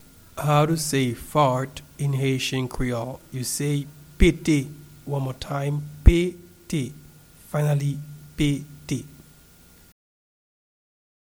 Pronunciation and Transcript:
Fart-in-Haitian-Creole-Pete-pronunciation-1.mp3